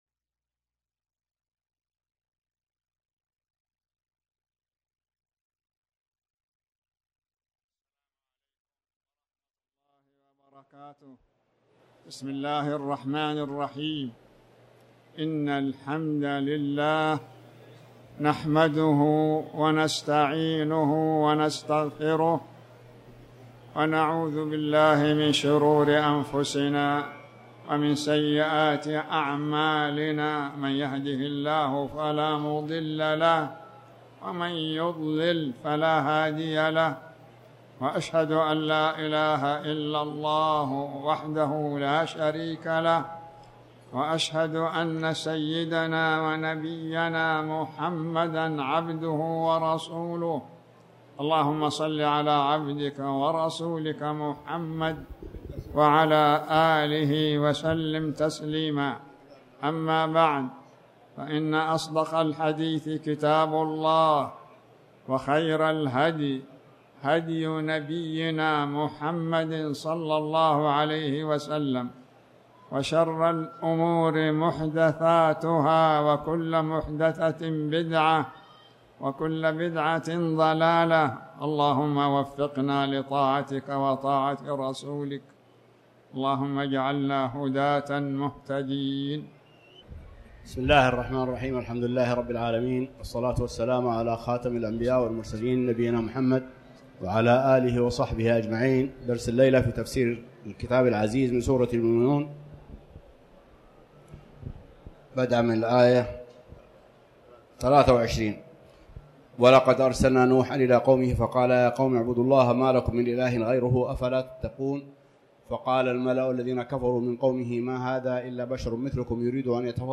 تاريخ النشر ١٨ شوال ١٤٣٩ هـ المكان: المسجد الحرام الشيخ